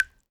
water_drop_drip_single_06.wav